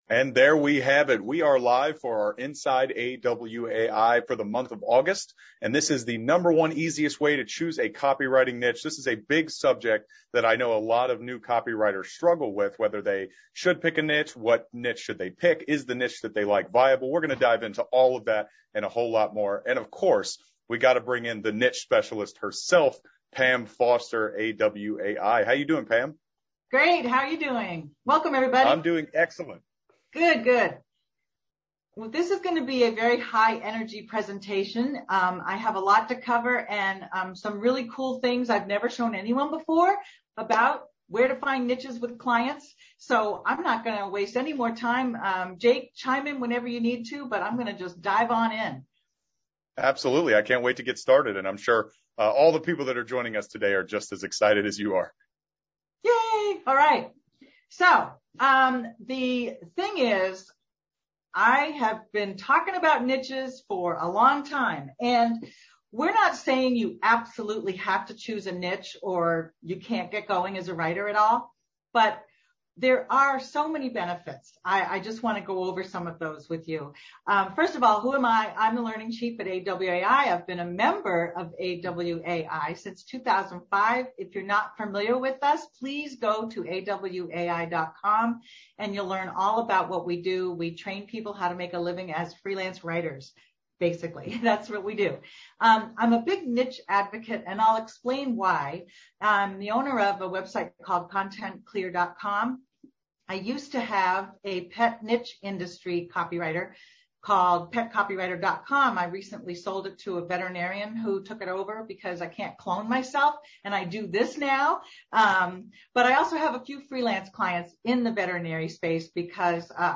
How to Choose a Profitable Copywriting Niche Webinar Playback Whether you’re just starting out as a copywriter or you’ve been working with freelance writing clients for a while, you likely already know that choosing a profitable niche has many advantages …